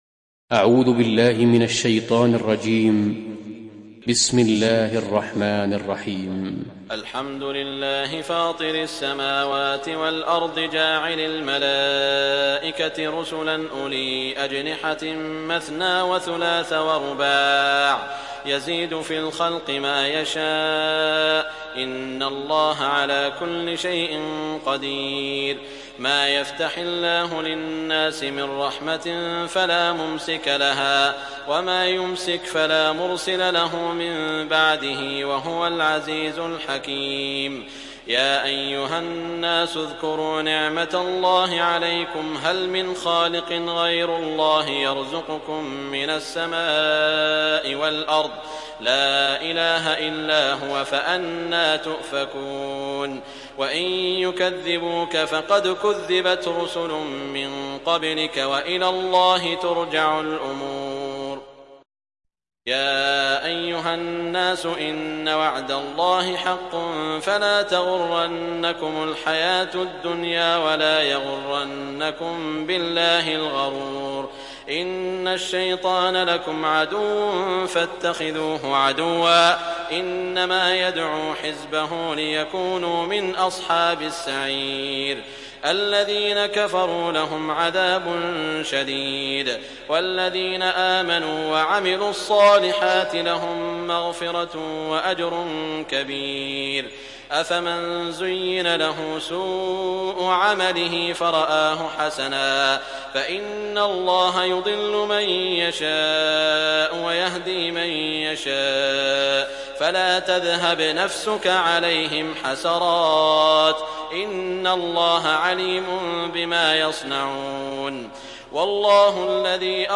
Surah Fatir mp3 Download Saud Al Shuraim (Riwayat Hafs)